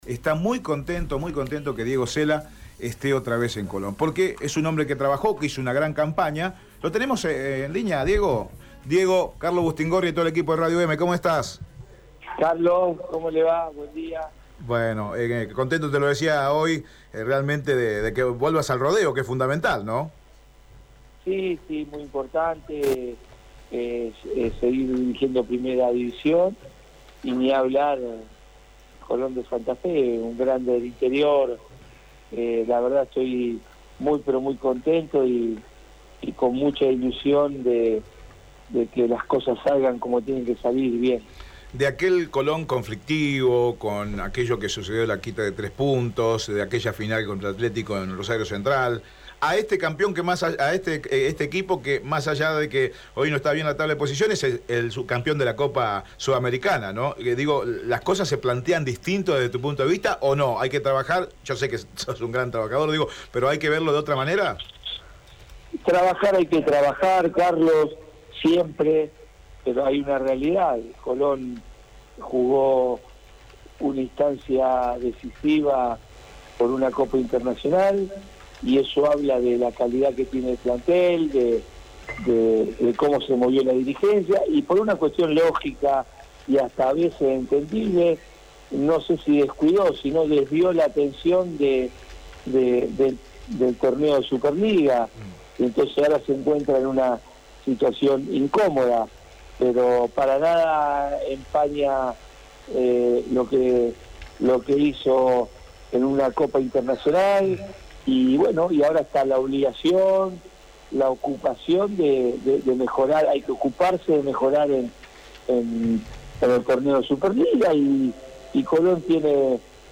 El nuevo director técnico de Colón, Diego Osella, habló con Radio EME Deportivo sobre su llegada al club rojinegro y afrontar los desafíos en su segundo paso por la institución.
entrevista